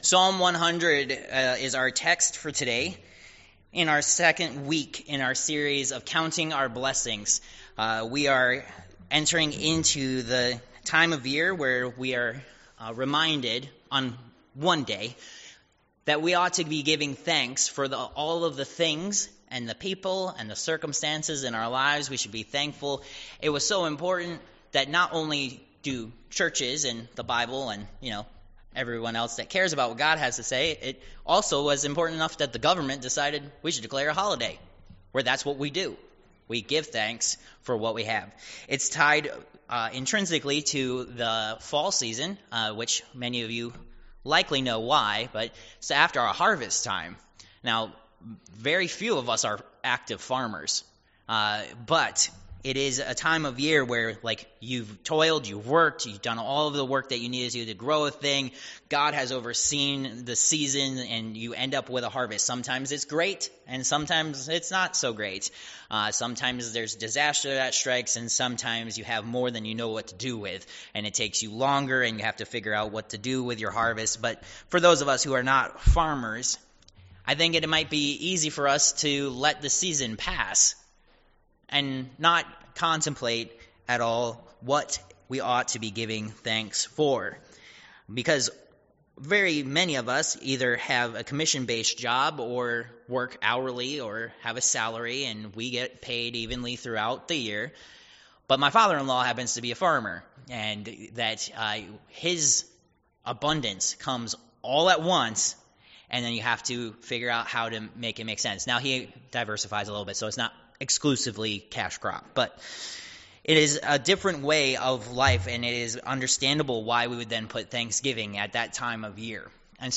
Col. 3:15-17 Service Type: Worship Service Topics: Thanksgiving « Protected